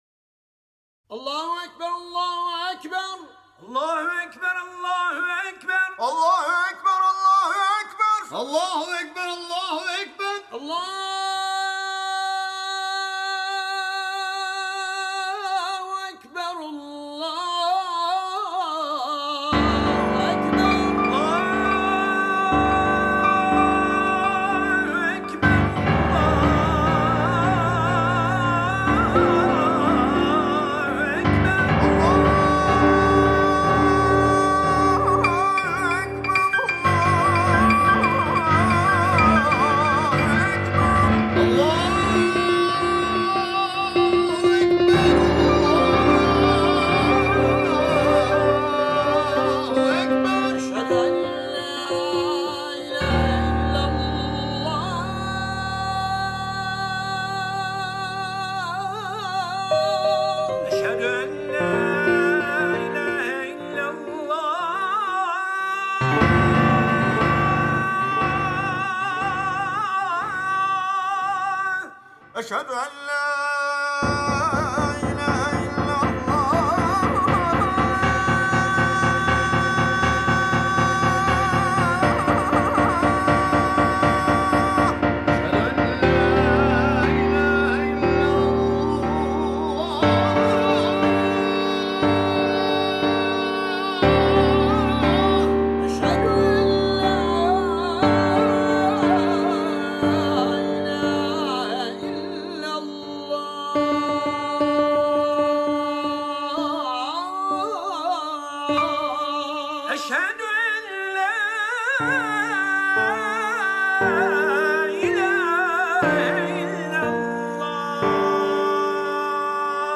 Koran(AZHAN) x Piano